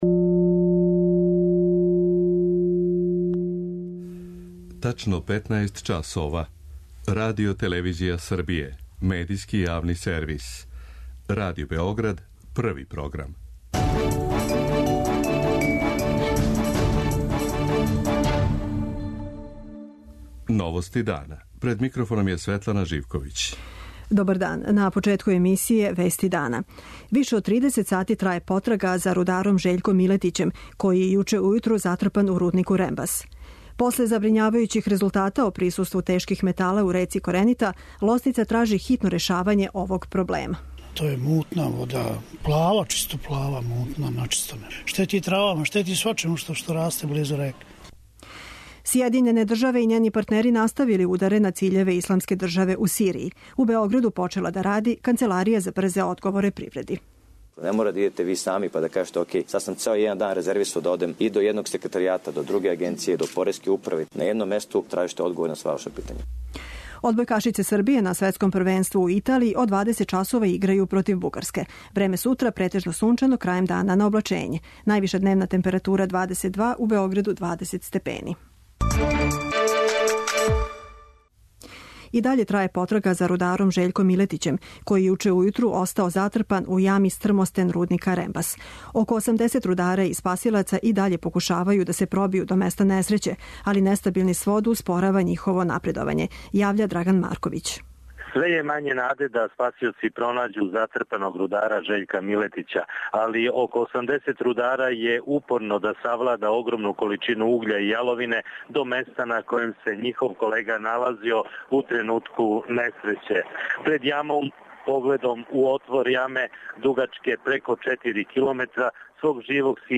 Репортер Београда 1 налази се на лицу места, чућете има ли помака у решавању проблема с истицањем јаловине.
преузми : 14.48 MB Новости дана Autor: Радио Београд 1 “Новости дана”, централна информативна емисија Првог програма Радио Београда емитује се од јесени 1958. године.